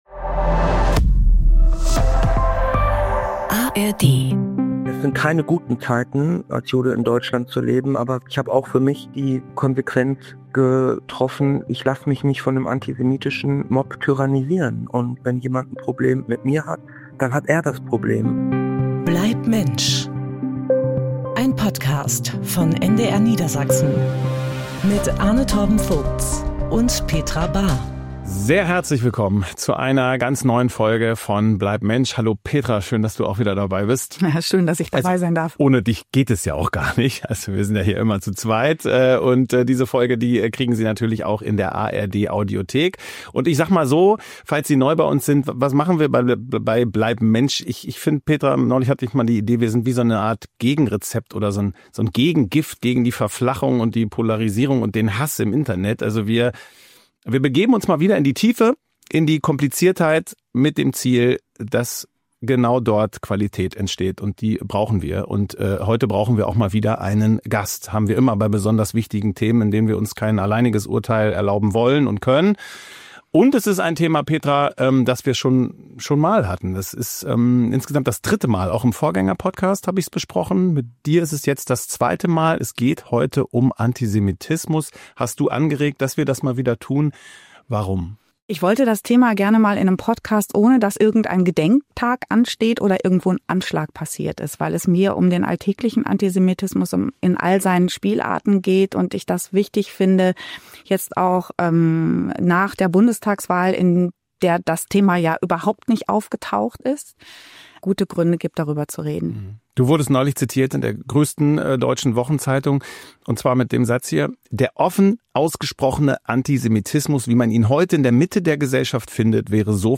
statt den Kopf in den Sand zu stecken und führen Gespräche mit Tiefgang, als Gegenpol zu den aufgeheizten Debatten unserer Zeit.